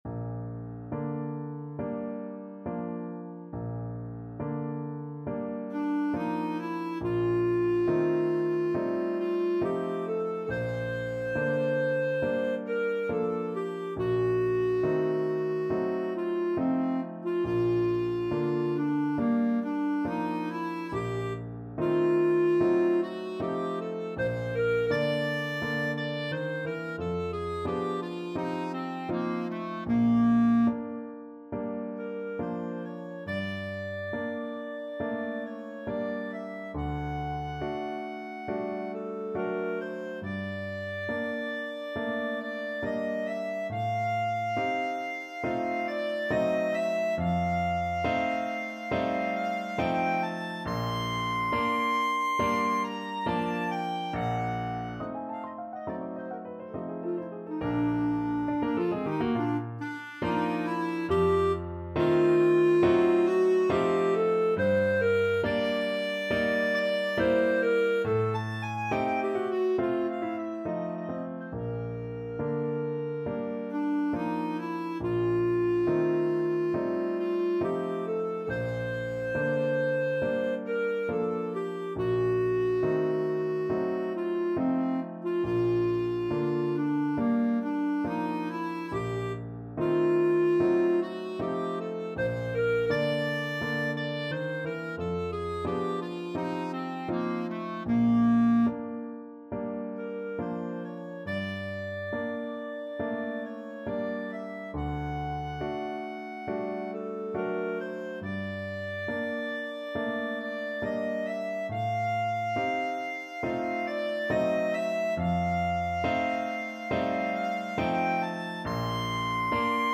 Clarinet
Andante non troppo con grazia =69
Bb major (Sounding Pitch) C major (Clarinet in Bb) (View more Bb major Music for Clarinet )
Classical (View more Classical Clarinet Music)
c_schumann_pno_concerto_am_2nd_mvt_CL.mp3